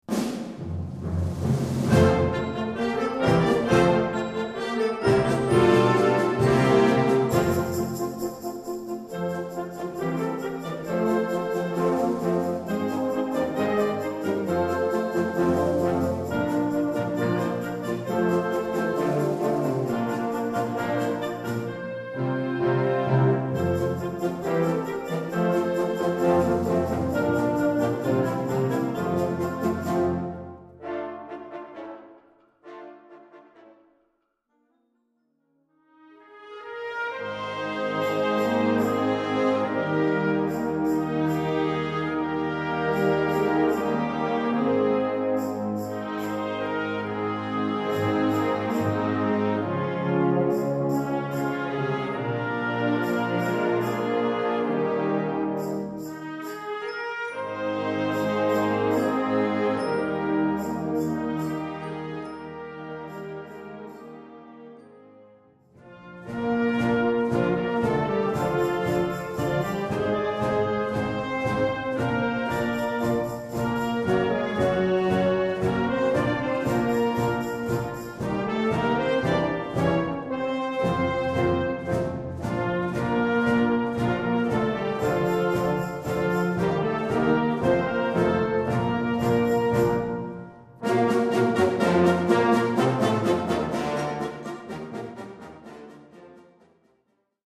Concert Band ou Harmonie ou Fanfare ou Brass Band